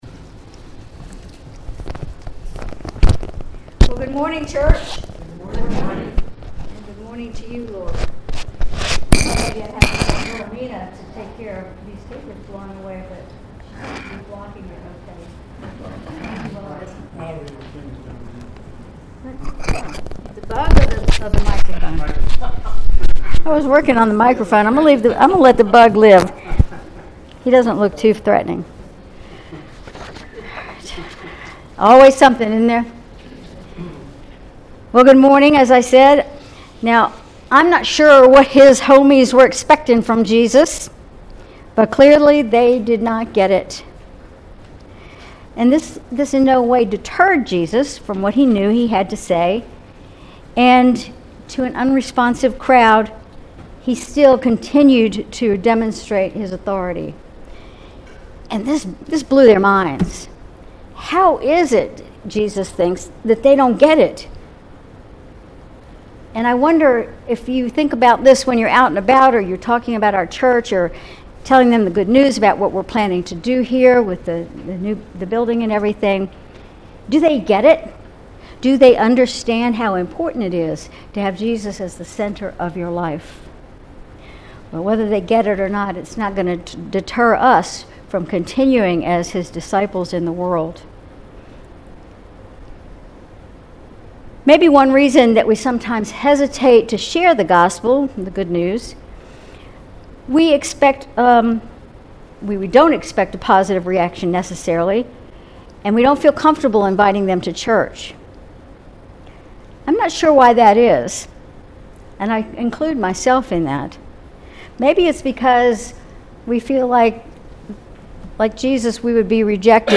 Sermon July 7, 2024
Sermon_July_7_2024.mp3